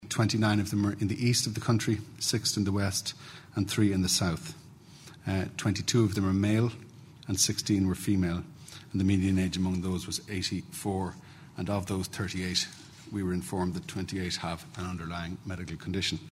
Chief Medical Officer, Dr. Tony Holohan outlines the details behind the 38 deaths: Listen to this audio